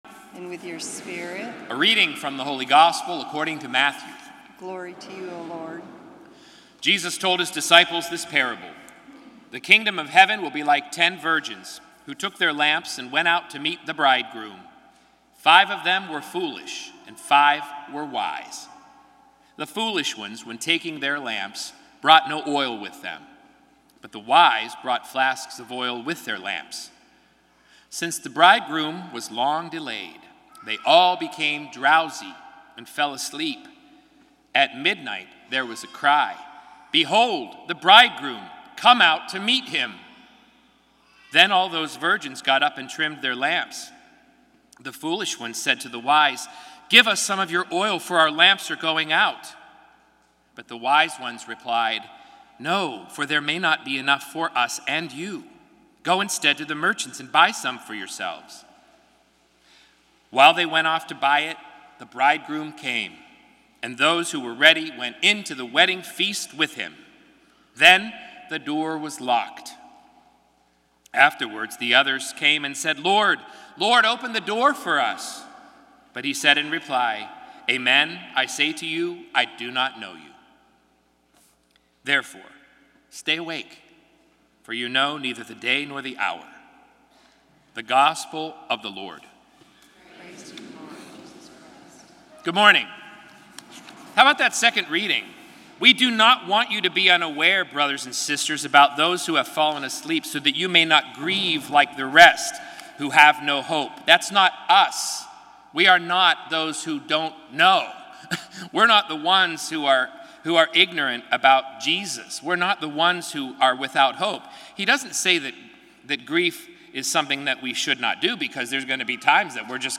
Gospel & Homily November 12, 2017